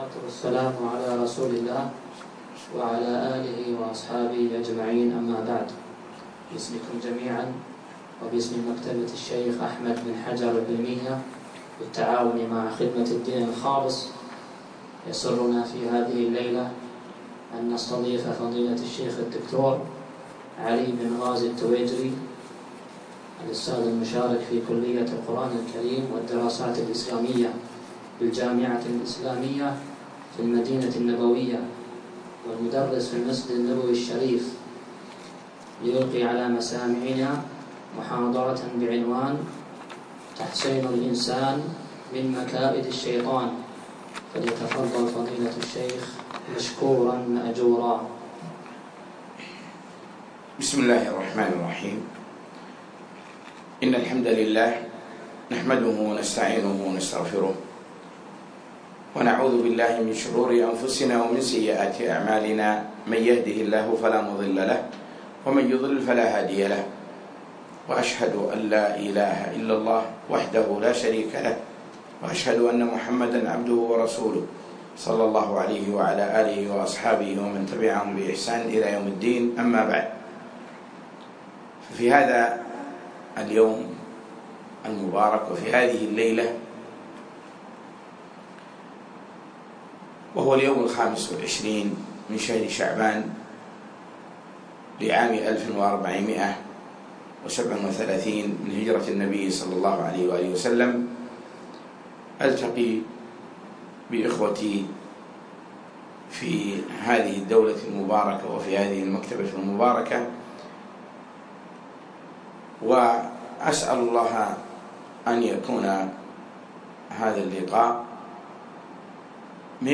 يوم الأربعاء 25 شعبان 1437هـ الموافق 2 6 2016م في مكتبة الشيخ أحمد بن حجر قطر